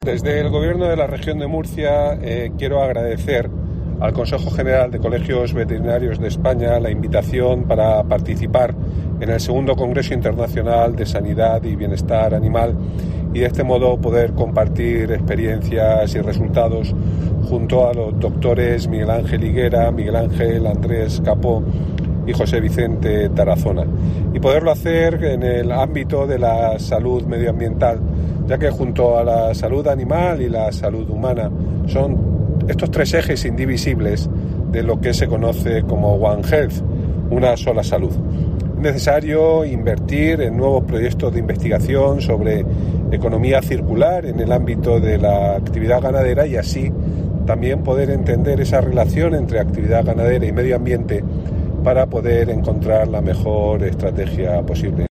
Juan María Vázquez, consejero de Medio Ambiente, Universidades, Investigación y Mar Menor